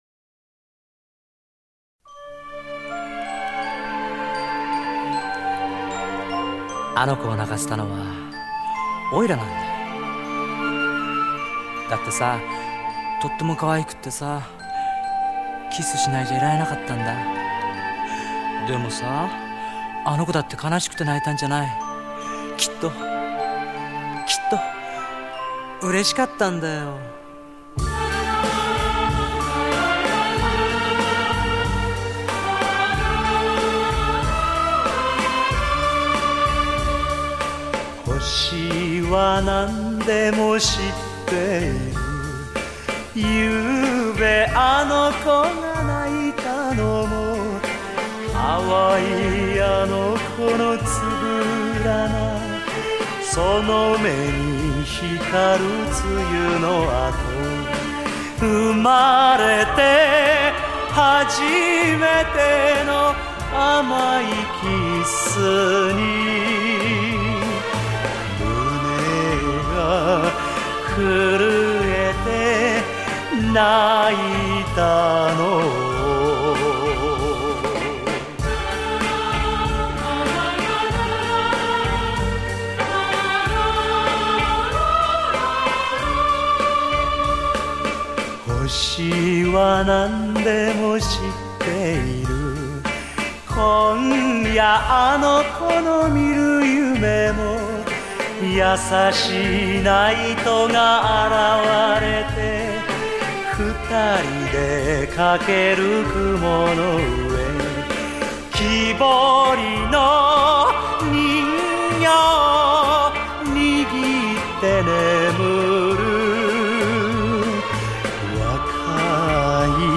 收录日本演歌精选/曲曲动听 朗朗上口